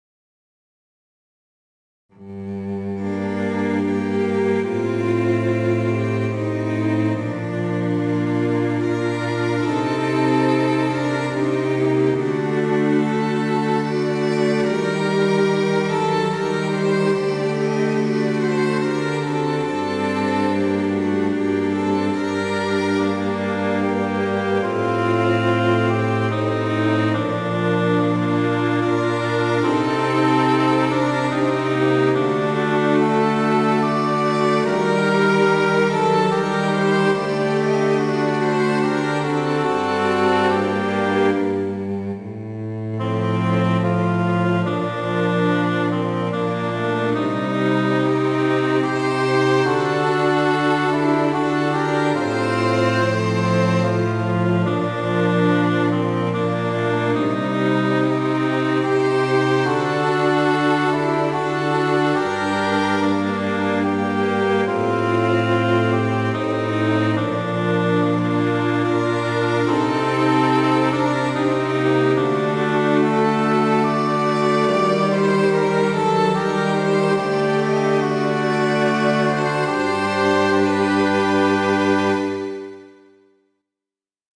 Midi Instrumental ensemble (Fl-Ob-Cl-Fg-Str)